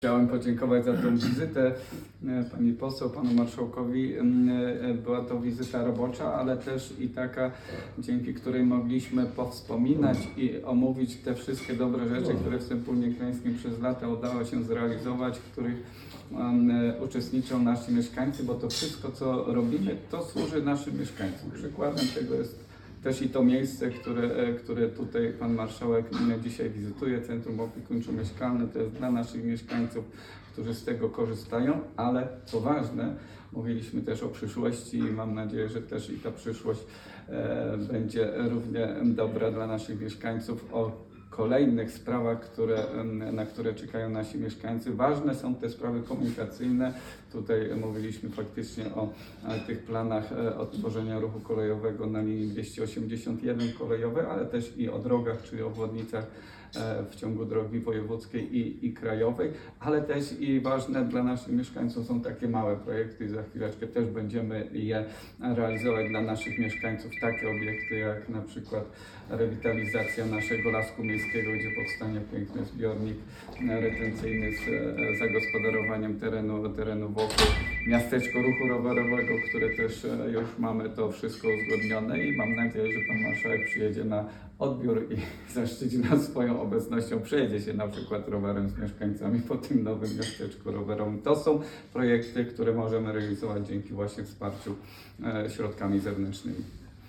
Z wizytą gospodarską w Sępólnie Krajeńskim
Wystąpienie burmistrza Sępólna Krajeńskiego Waldemara Stupałkowskiego: